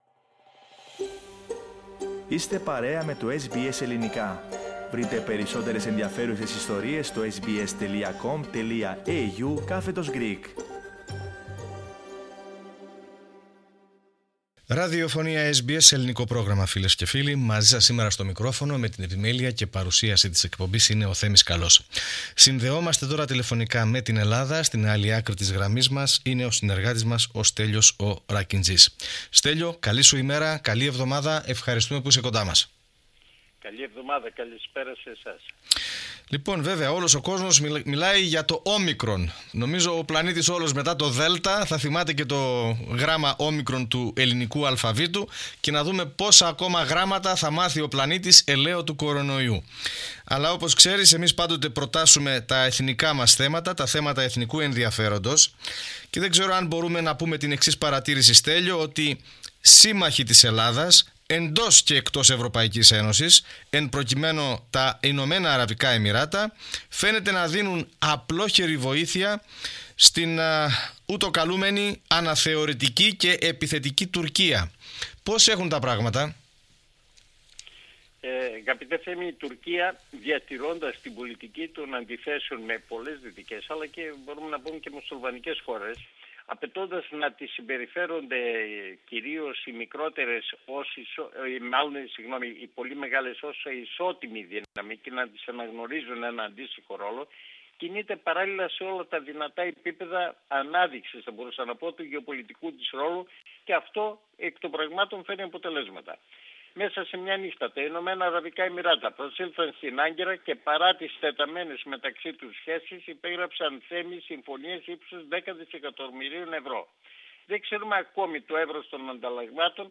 Πατήστε PLAY πάνω στην εικόνα για να ακούσετε περισσότερα για το θέμα αυτό και την ανταπόκριση του SBS Greek / SBS Ελληνικά από την Ελλάδα.